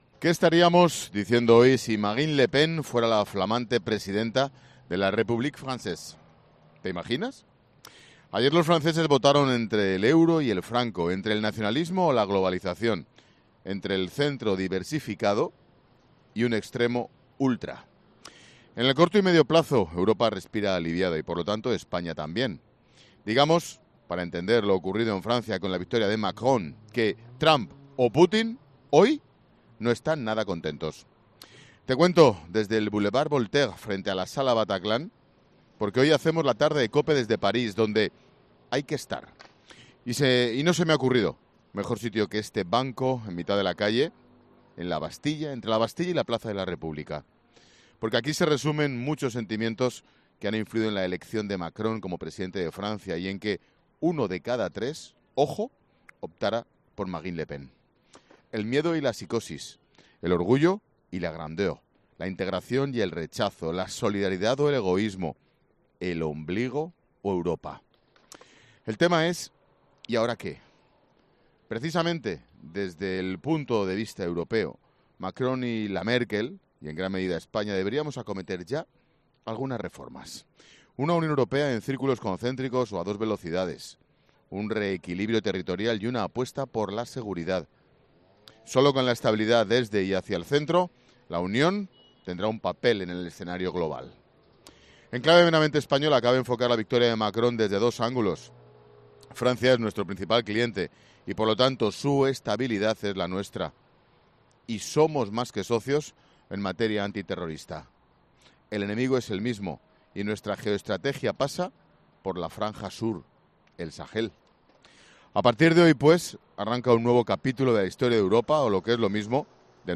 AUDIO: Monólogo 16h.